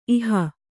♪ iha